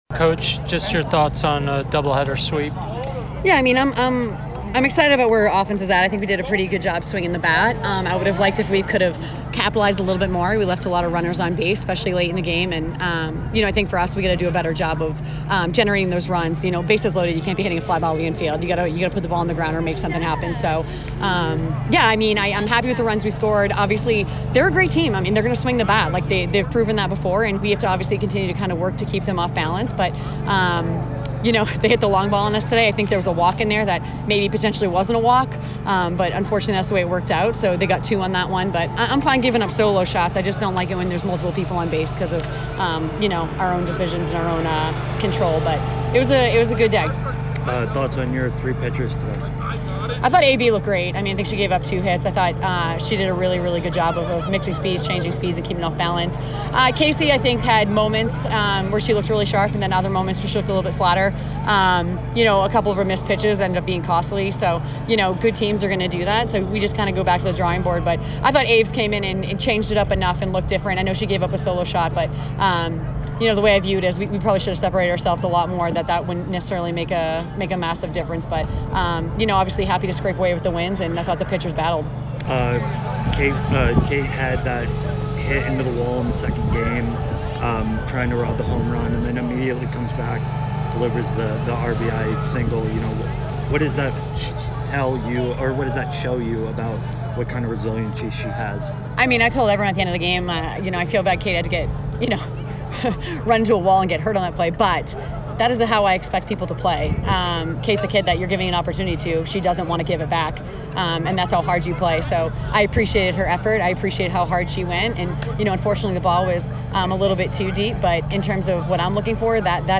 Softball / Army DH Postgame Interview (4-28-23) - Boston University Athletics